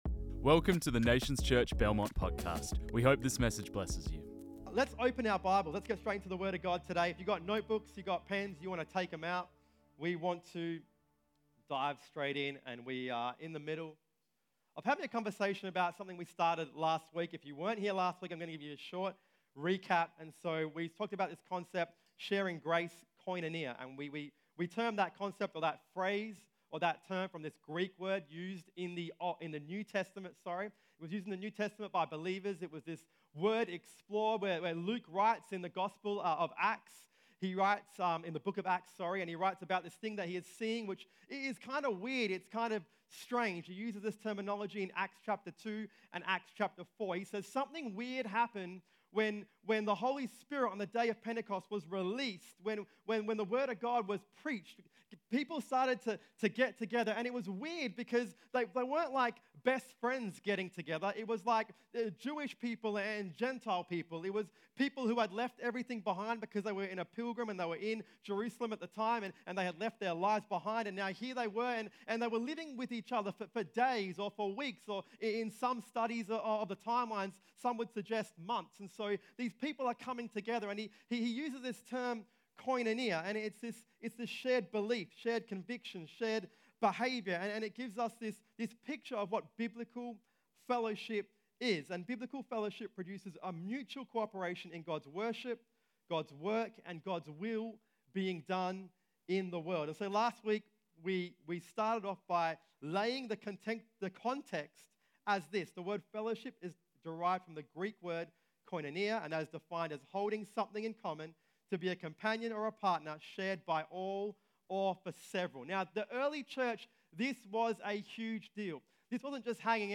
This message was preached on 29 January 2023.